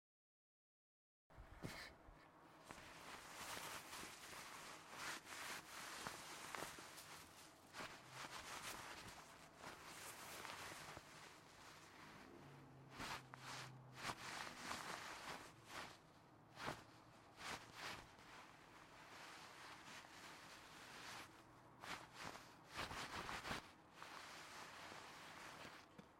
描述：记录了某人以快速和慢速的方式摩擦材料。使用H6变焦记录器和胶囊完成录制。
标签： 纺织 擦拭物 材料 摩擦 织物 OWI 喷粉 帆布 摩擦
声道立体声